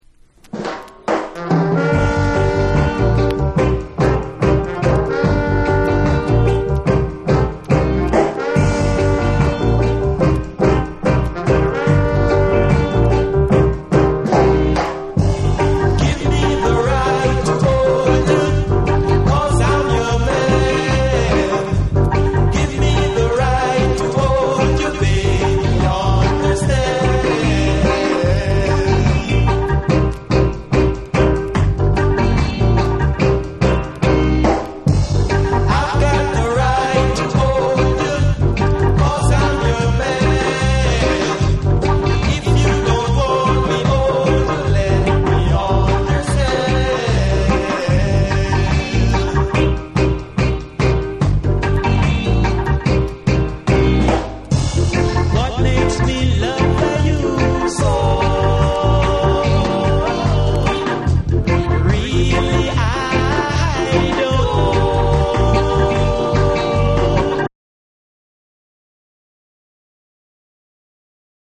（ジャマイカ盤につきチリノイズ多し）
REGGAE & DUB